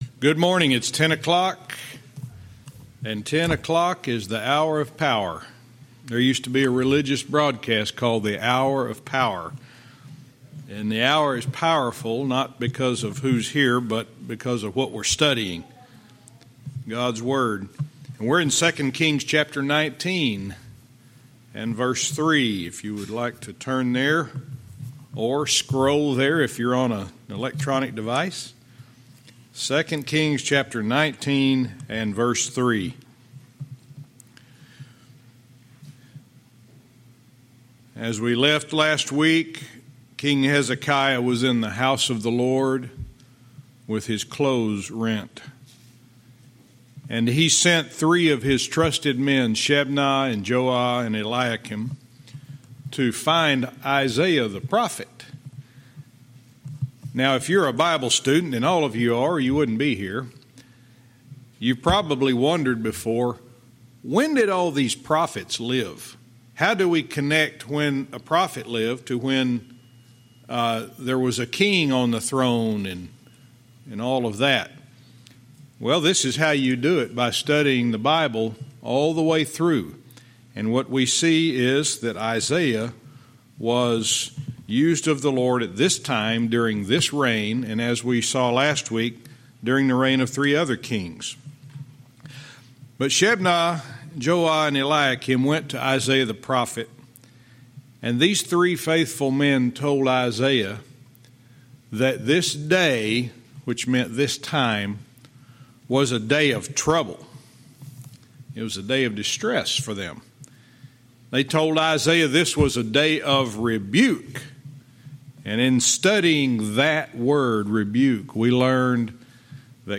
Verse by verse teaching - 2 Kings 19:3-4